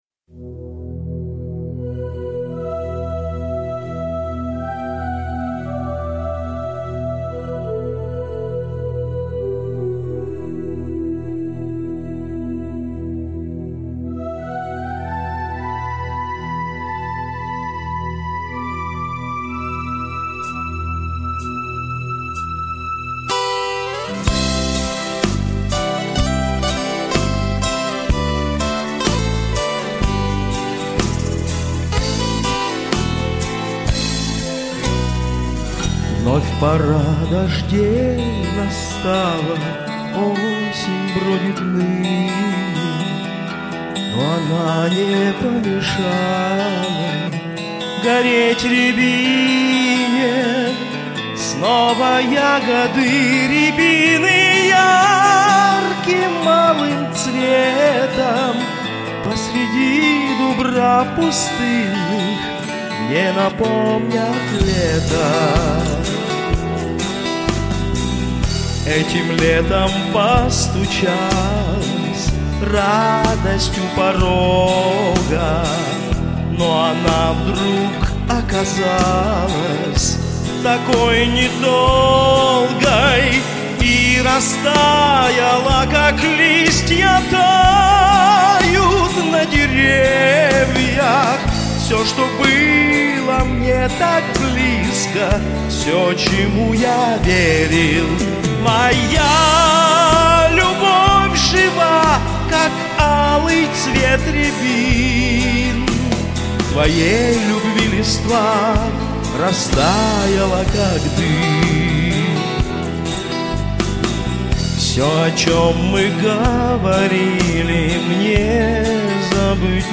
мягкое исполнение, глубокое, грудное, протяжное